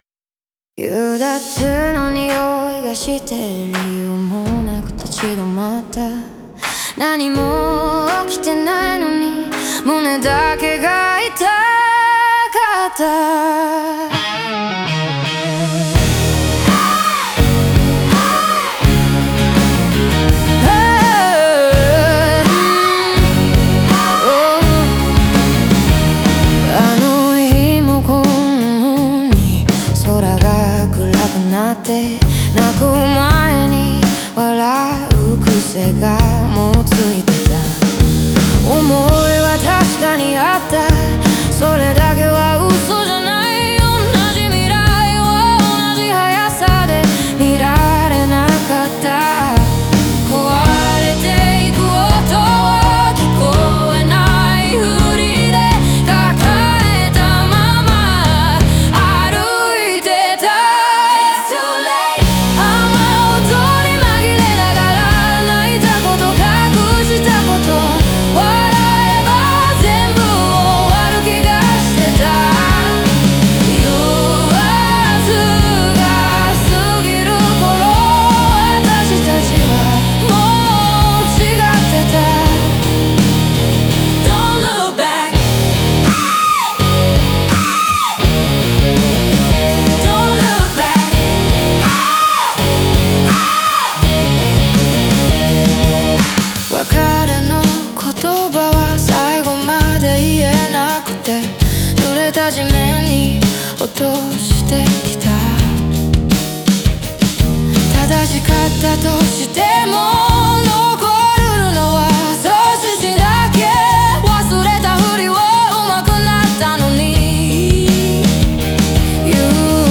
結果として、激しさと切なさが同居する、余韻の残る感情の放出が描かれている。